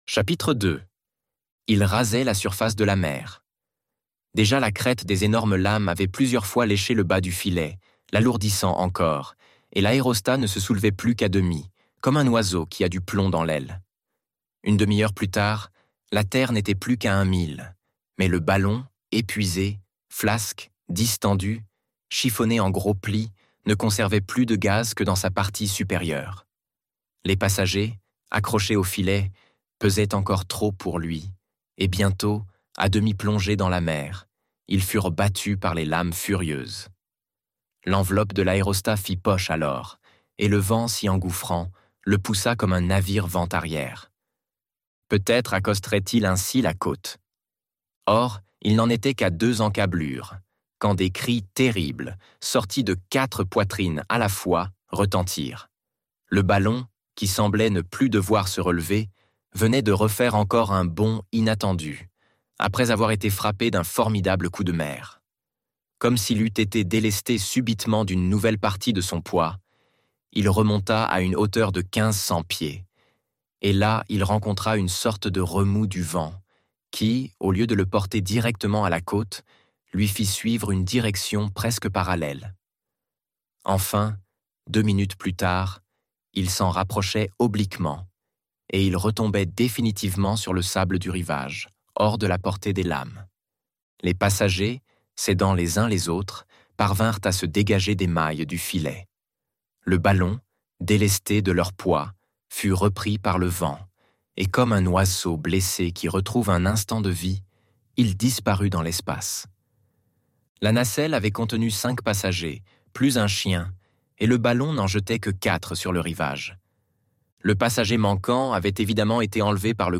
L'Île mystérieuse - Livre Audio